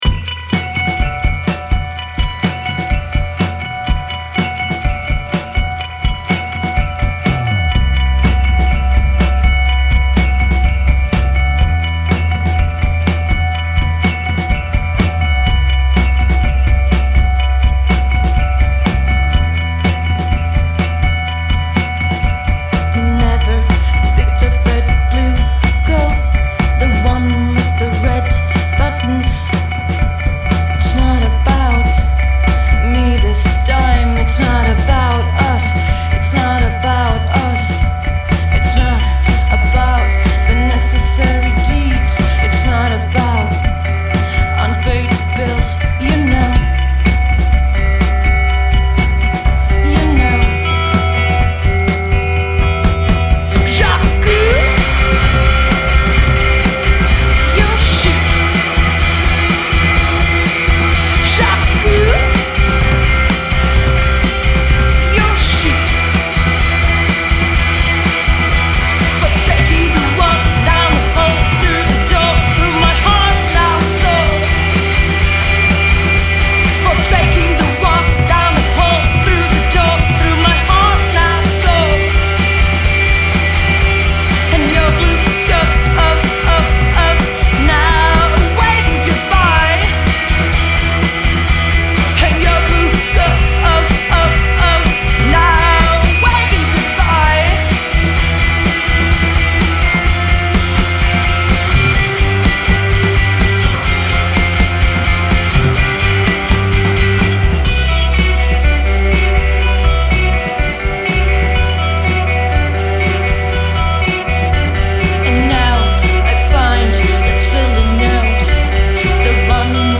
"This all-girl Cleveland foursome packs a helluva
punch, with loud, edgy pop/rock and no apologies.